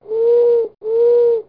owl.mp3